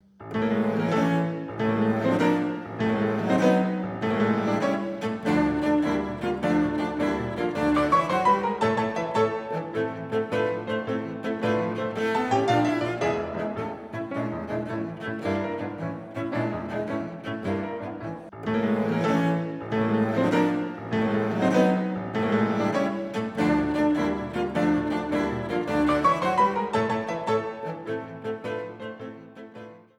Sonate für Violoncello und Klavier D-Dur: Presto